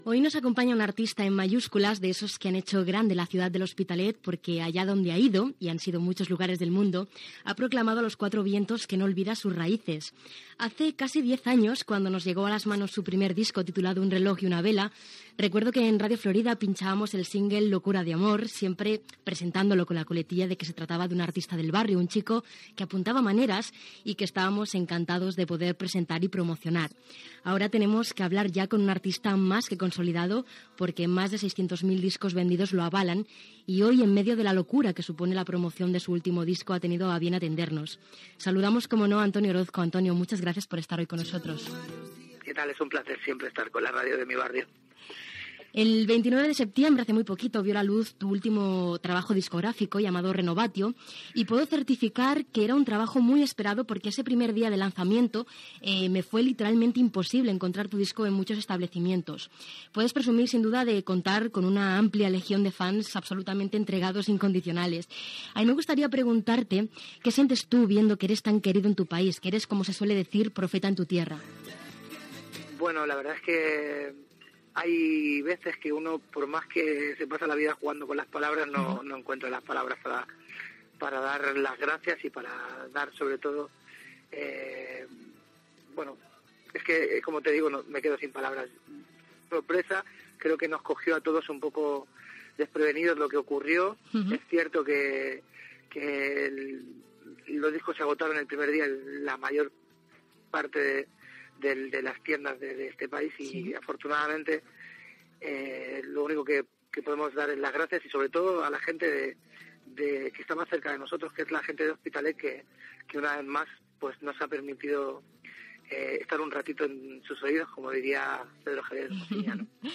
Entrevista al cantant Antonio Orozco que presenta el disc "Renovatio".